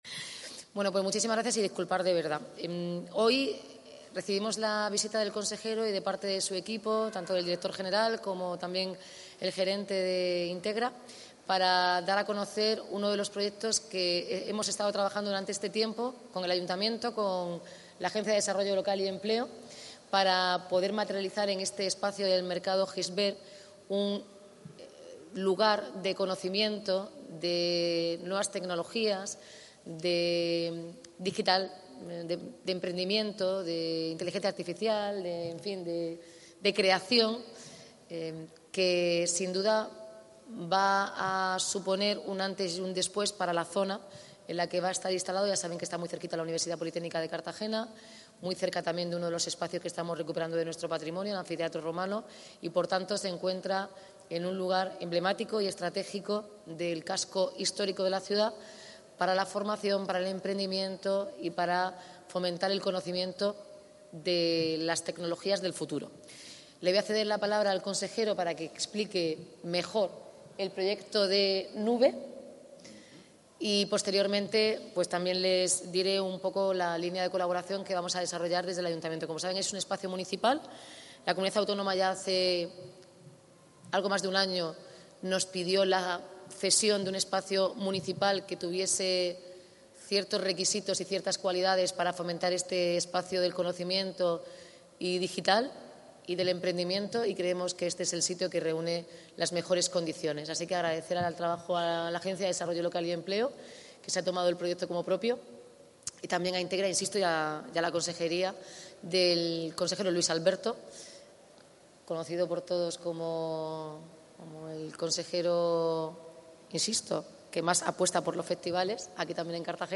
La alcaldesa de Cartagena, Noelia Arroyo, ha visitado junto al consejero de Hacienda, Luis Alberto Marín, las nuevas instalaciones que cuentan con una superficie cercana a los 300 m², “consideramos que el mejor sitio era un espacio en el casco histórico que sirva también de revulsivo para dinamizar el centro de la ciudad”, ha destacado Arroyo.